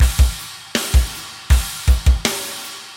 OVERDRIVE MUSIC - Boucle de Batteries - Drum Loops - Le meilleur des métronomes
METAL
Half time - China
Straight / 160 / 1 mes